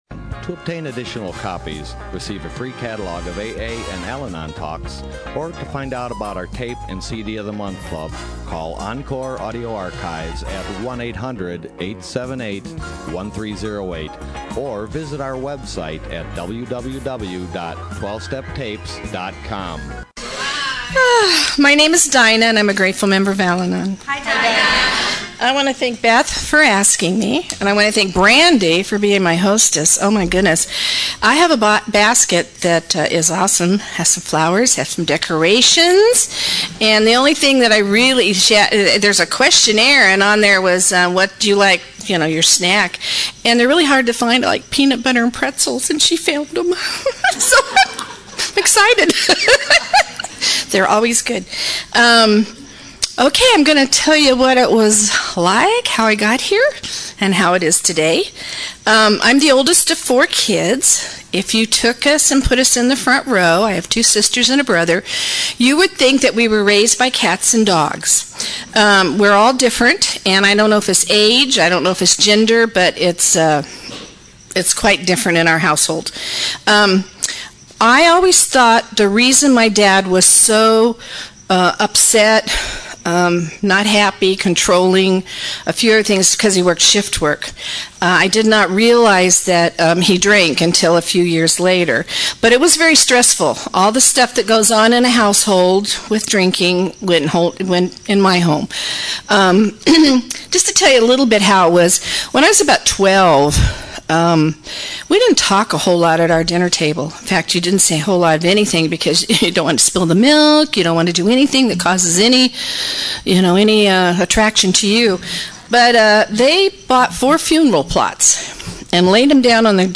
SoCAL AA Convention